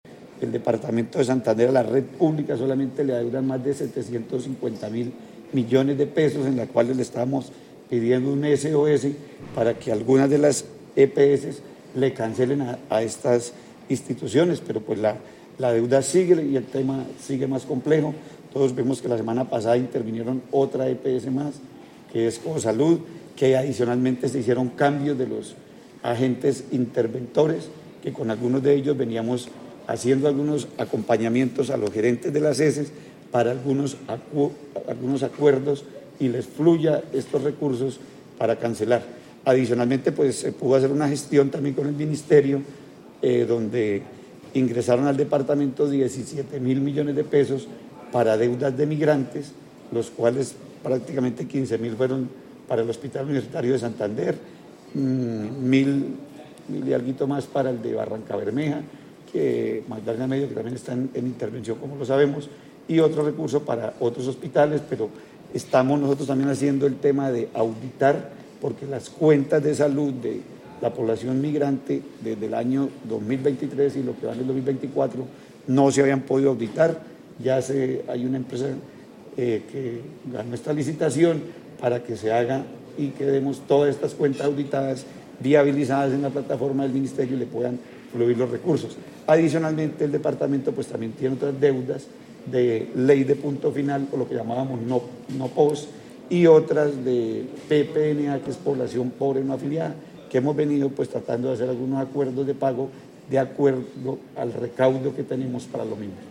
Secretario de Salud de Santander, Edwin Prada